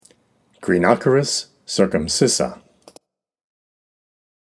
Pronunciation/Pronunciación:
Greene-ó-cha-ris cir-cum-scís-sa